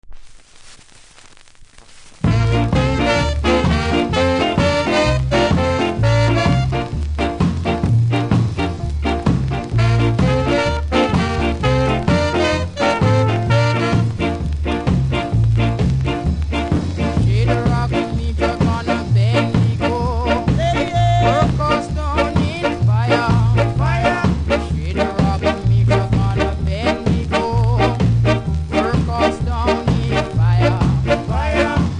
少しプレスノイズあります。